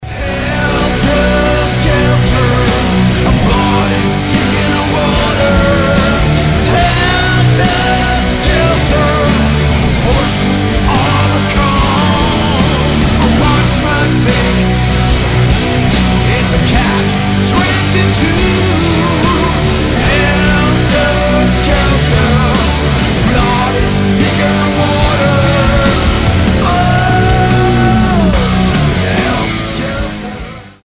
• Качество: 320, Stereo
мужской вокал
громкие
мощные
Alternative Metal
Alternative Rock
Industrial metal